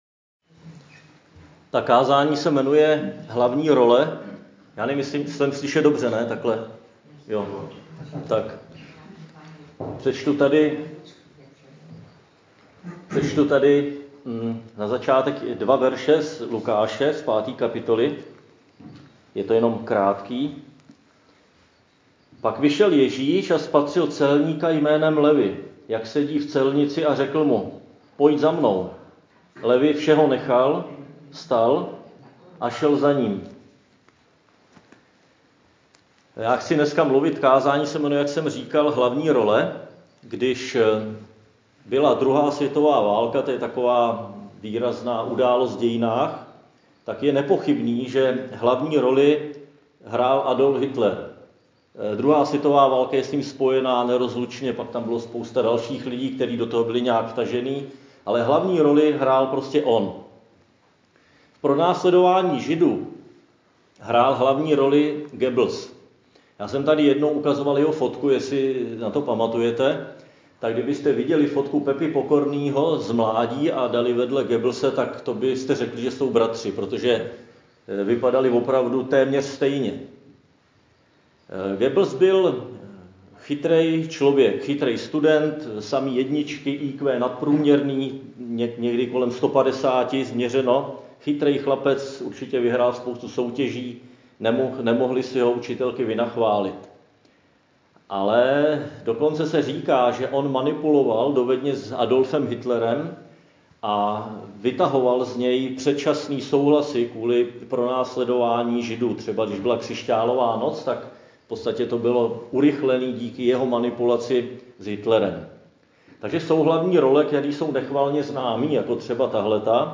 Křesťanské společenství Jičín - Kázání 21.3.2021